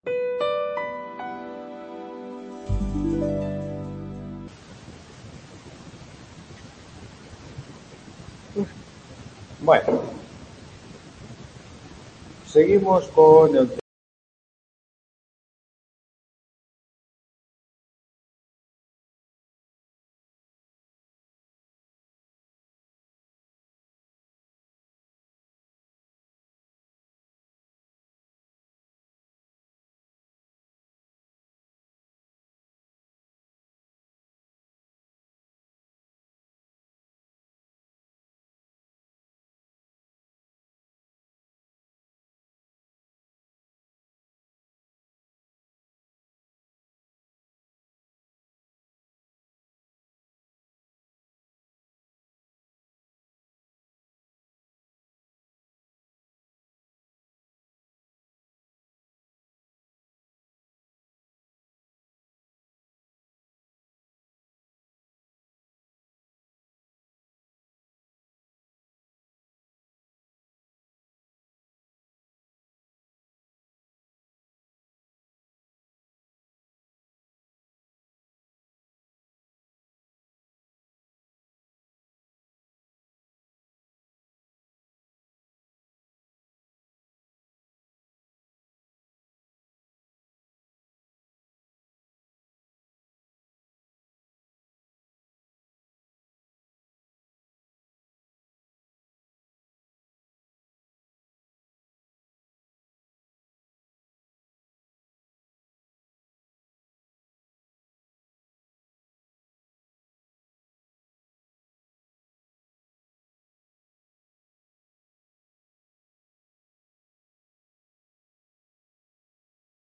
Tutoría 7